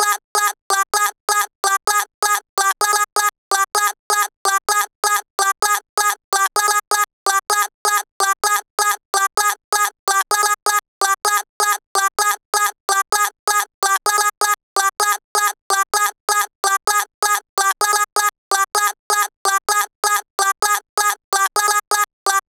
NRG Lead1 3.wav